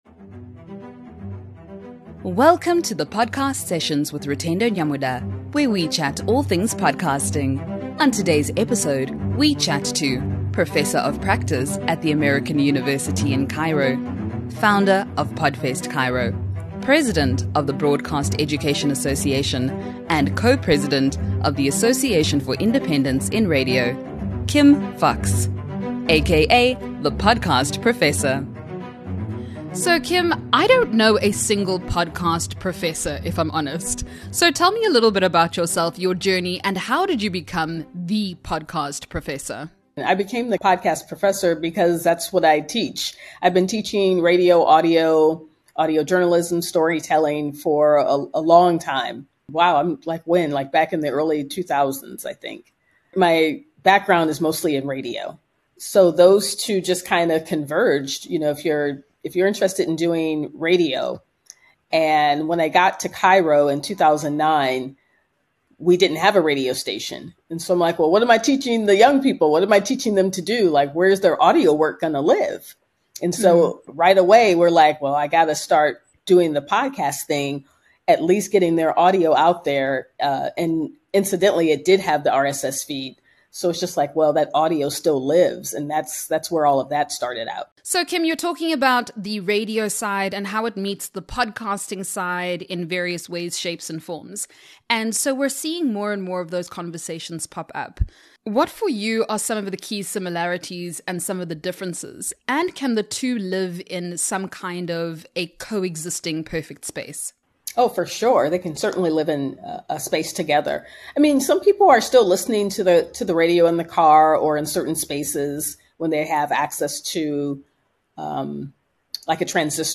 takes you behind the mic with podcast hosts, producers and experts in the industry.